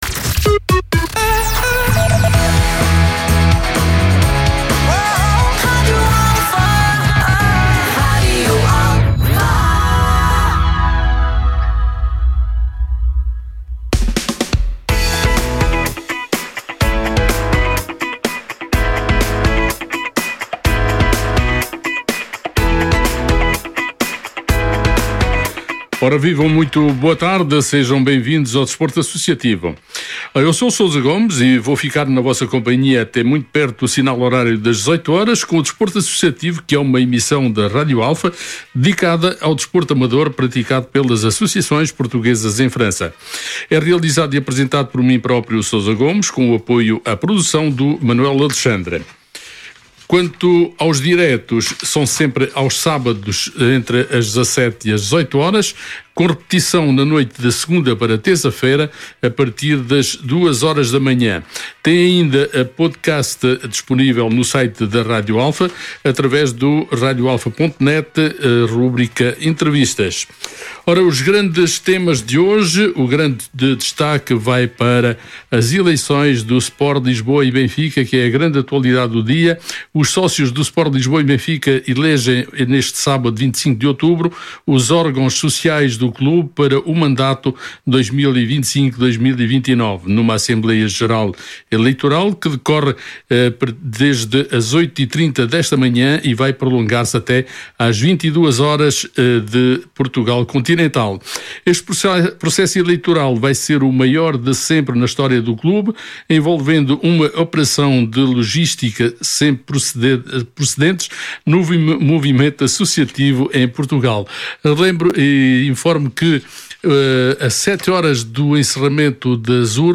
Desporto Associativo, todos os Sábados, entre as 17h e as 18h (redifusão às 2h, na noite de segunda para terça-feira).